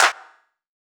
YCLAPS.wav